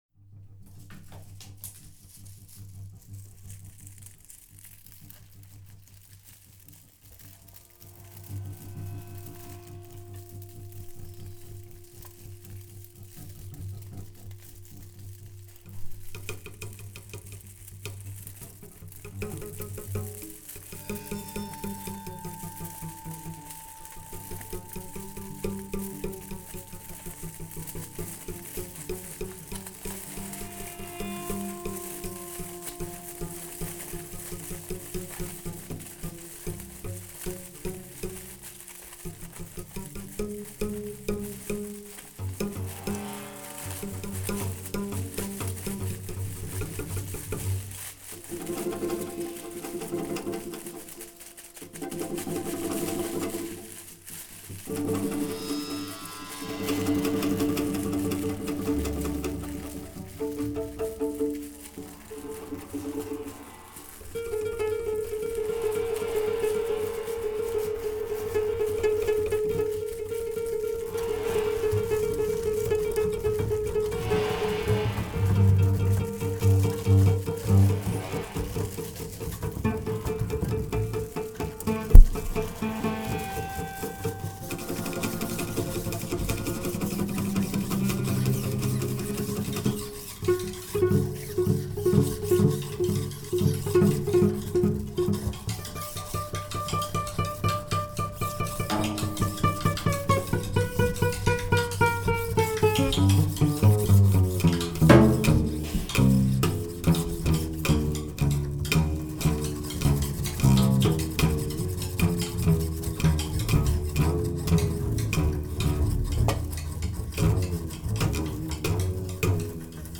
cello
double bass
drums and percussions